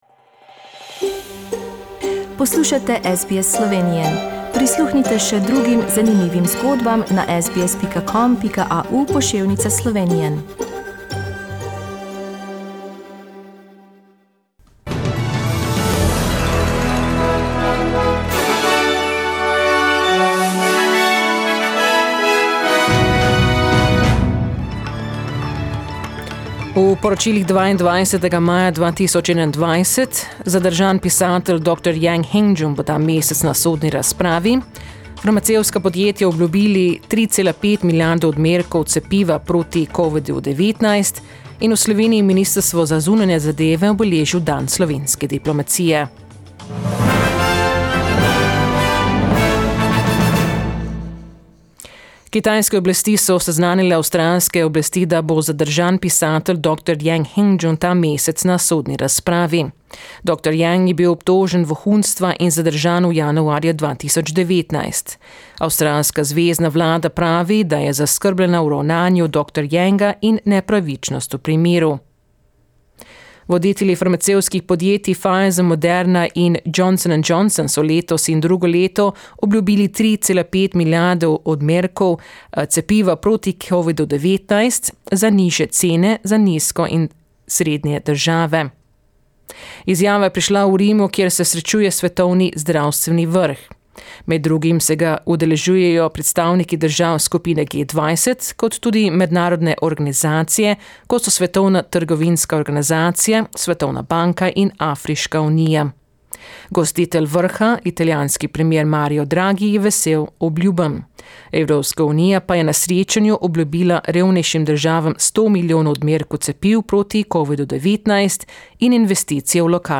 SBS News in Slovenian - 22nd May, 2021
Listen to the latest news headlines in Australia from SBS Slovenian radio.